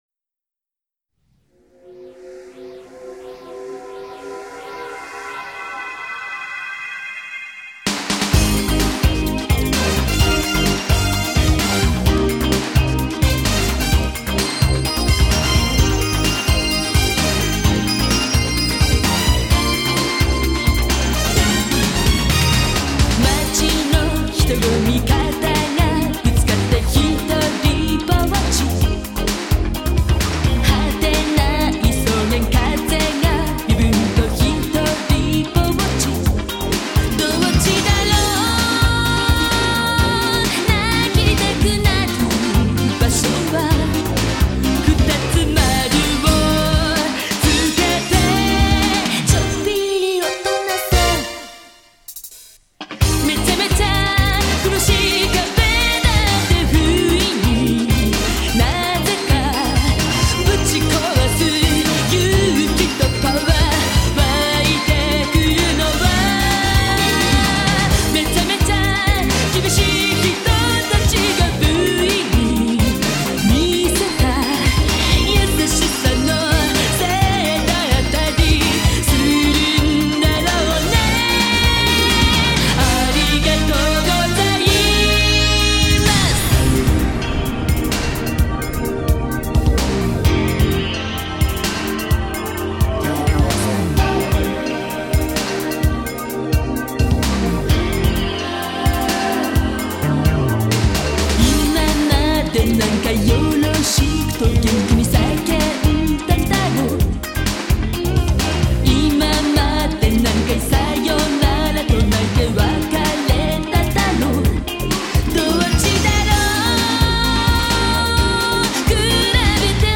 Prima e seconda sigla di Apertura